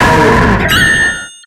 Cri de Bastiodon dans Pokémon X et Y.